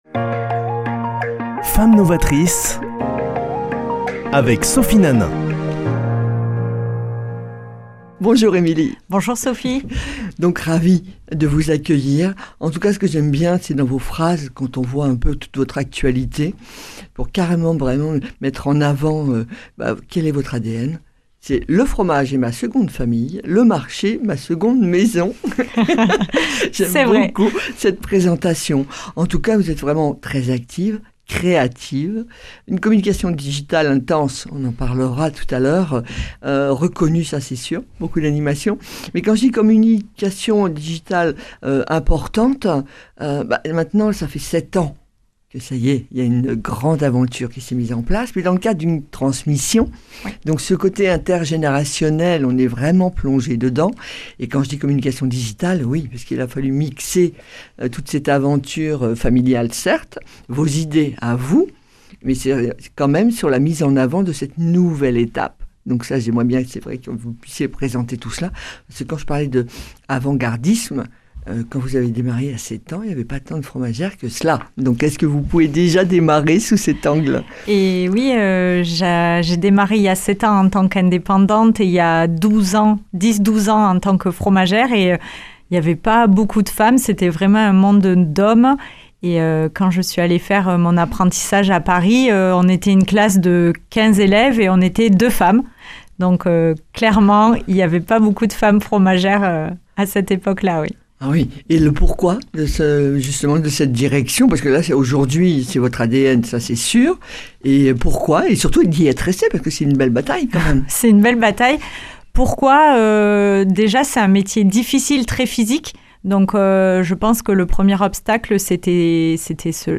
lundi 11 décembre 2023 Chronique Femmes novatrices Durée 8 min
Présentatrice